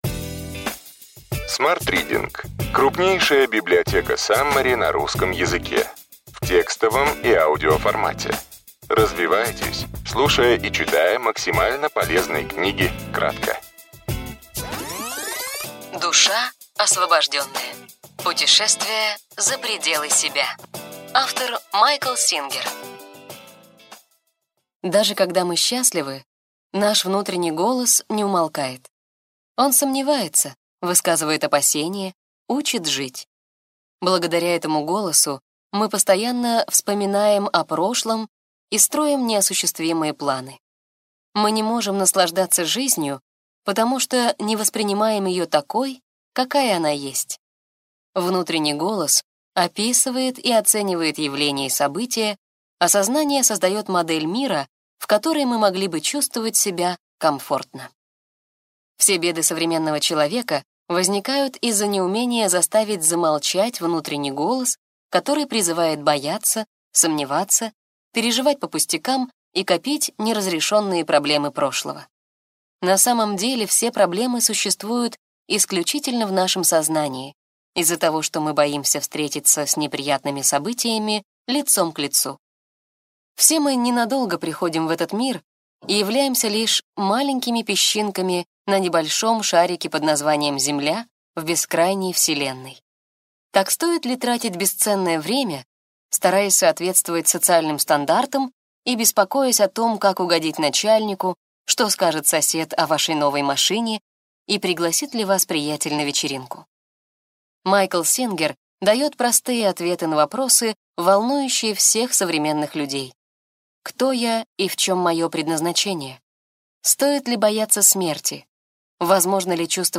Aудиокнига Mindfulness. Осознанное отношение к себе и жизни. 12 книг в одной